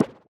TennisHitC.wav